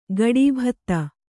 ♪ gaḍībhatta